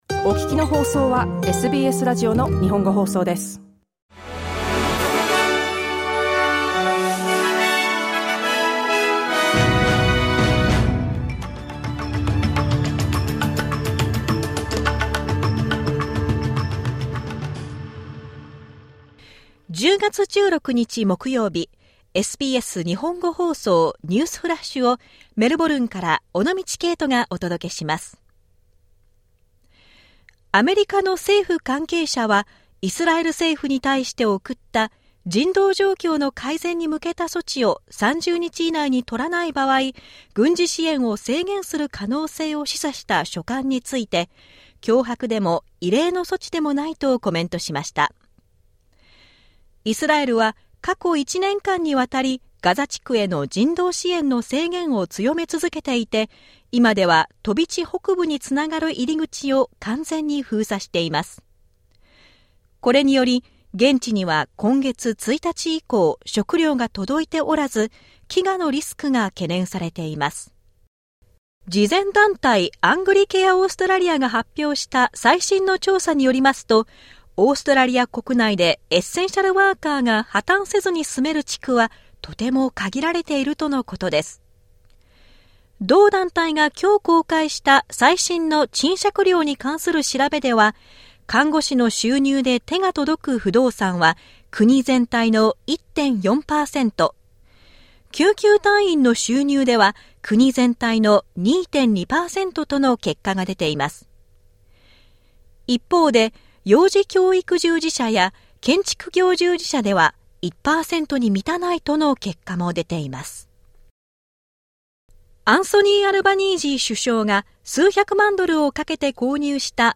SBS日本語放送ニュースフラッシュ 10月16日 水曜日